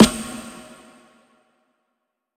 Snare (11).wav